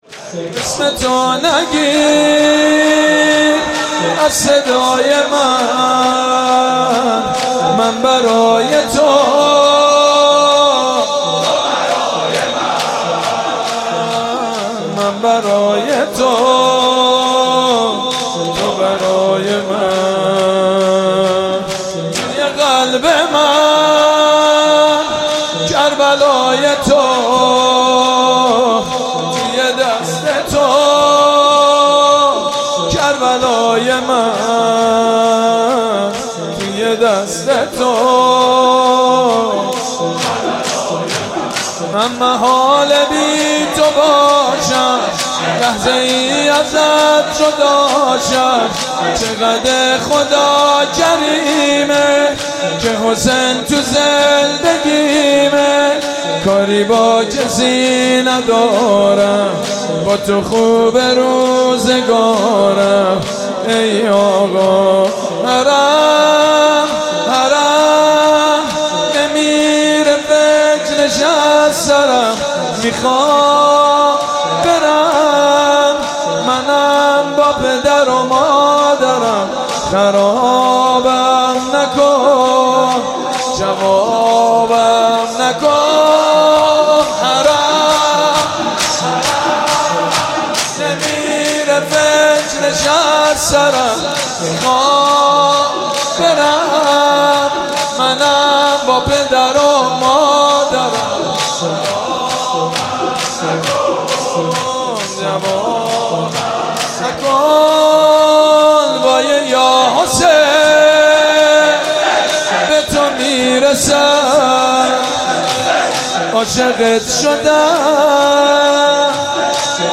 اسمتو نگیر از صدای من | شور